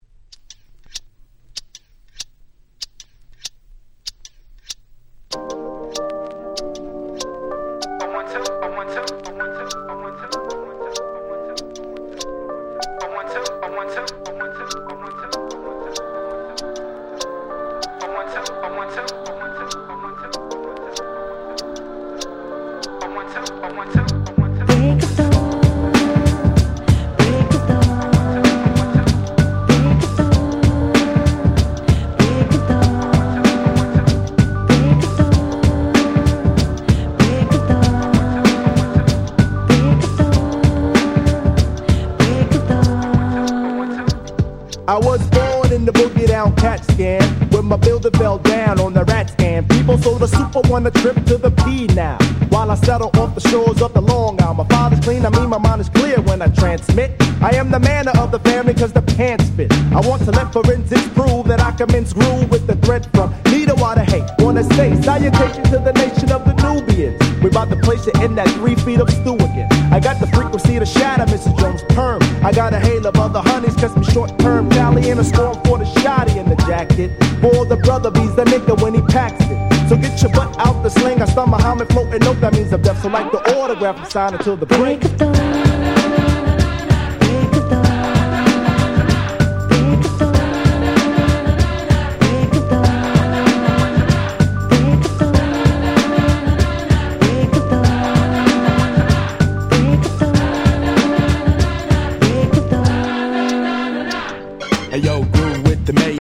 93' Smash Hit Hip Hop !!
90's Boom Bap